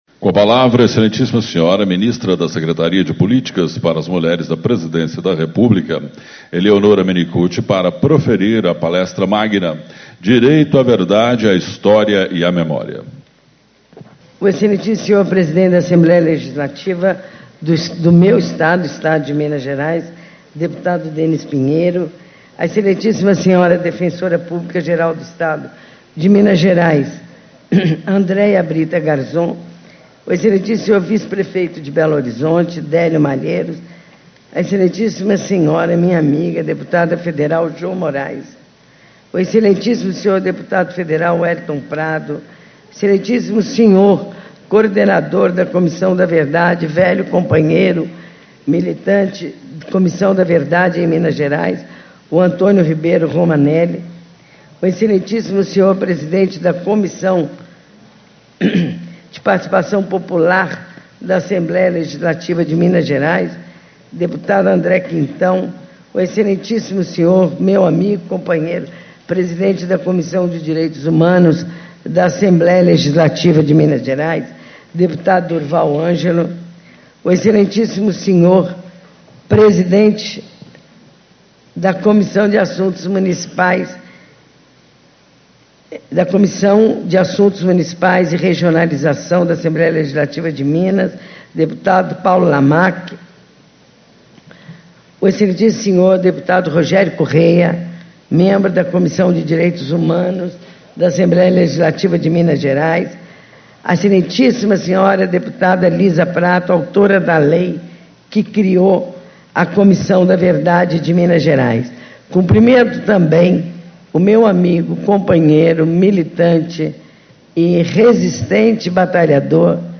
Palestra Magna -